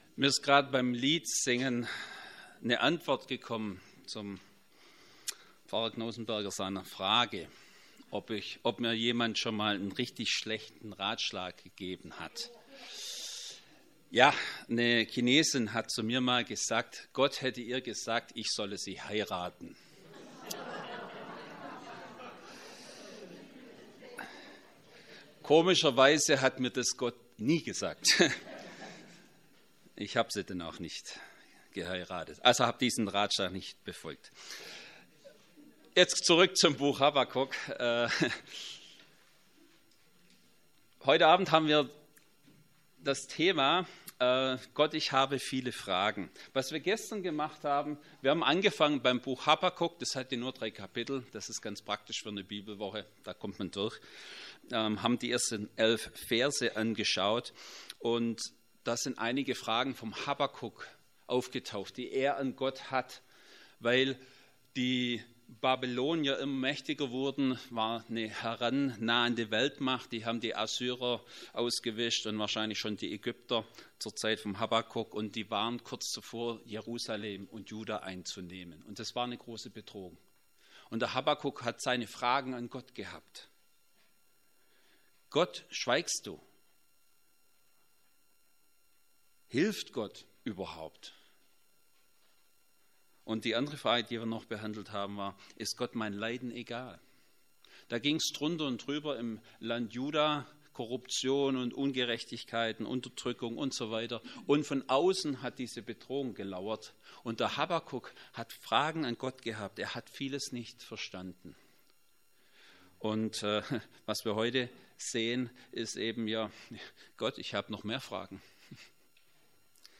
Habakuk 1,12-2,5 Predigt.mp3